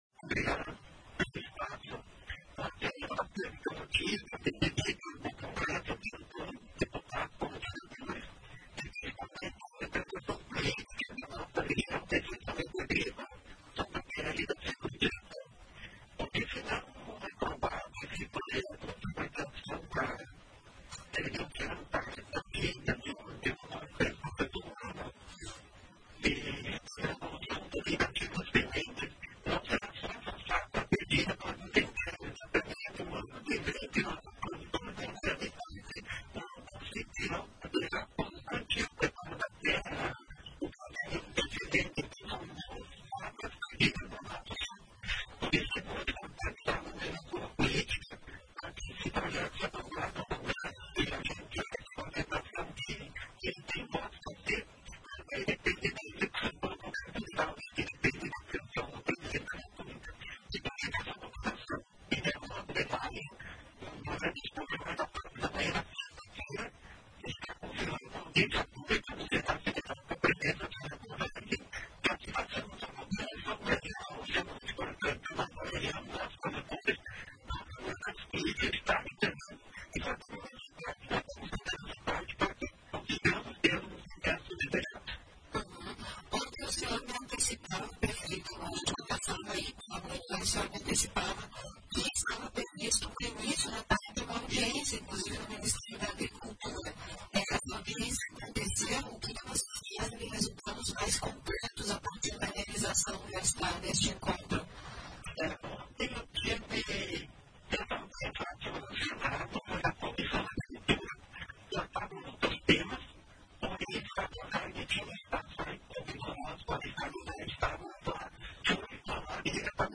ENTREVISTA-PREFEITO-JOIA-2003.mp3